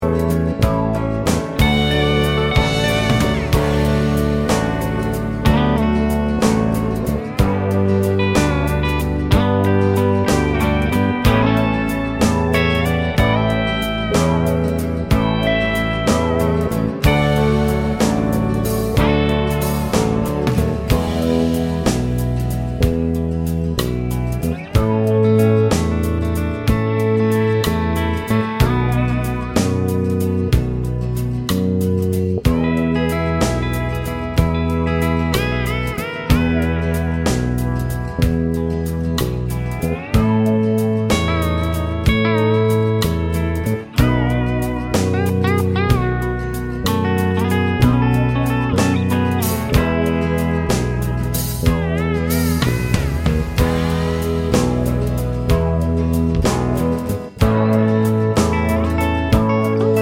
no Backing Vocals Country (Male) 2:55 Buy £1.50